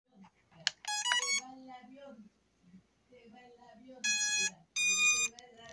HS720E DRONE'S POWER UP SOUND